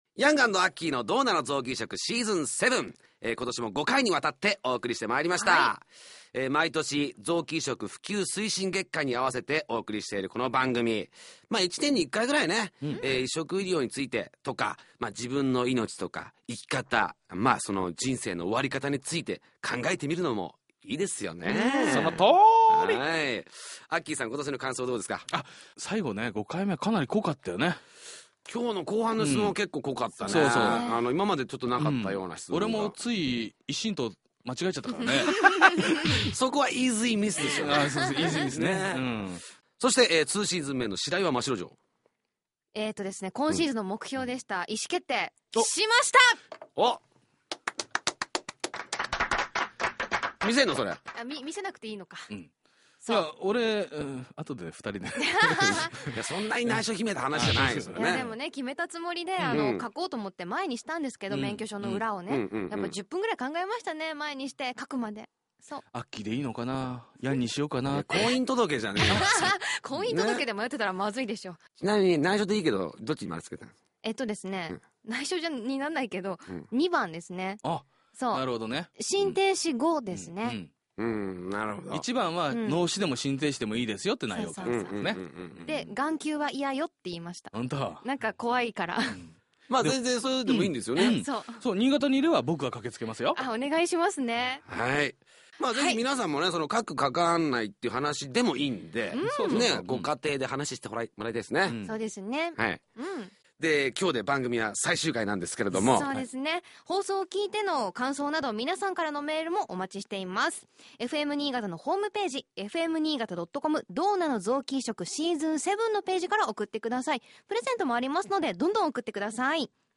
※BGMやリクエスト曲、CMはカットしています。